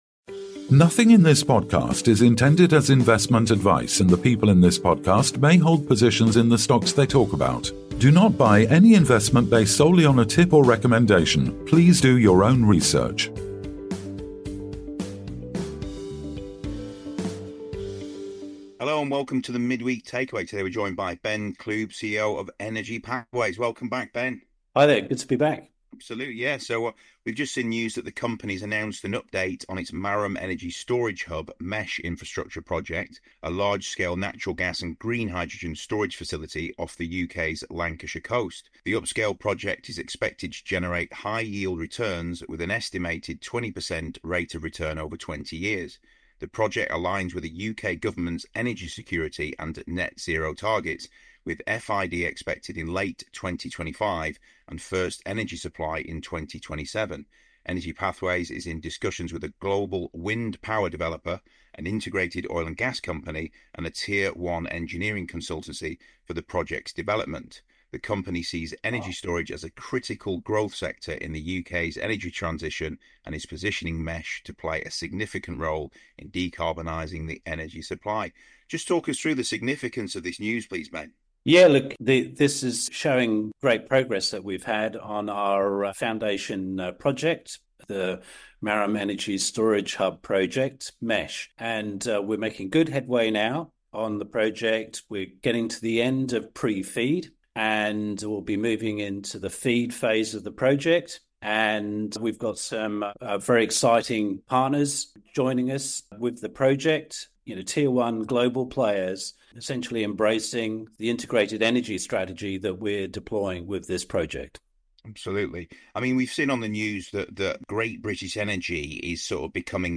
Don't miss this insightful conversation on the future of energy storage!